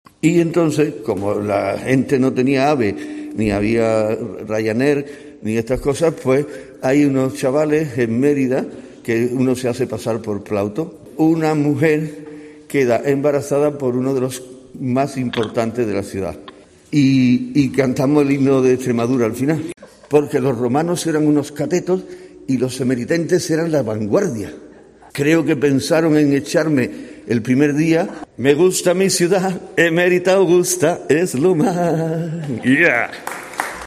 en la presentación de la obra.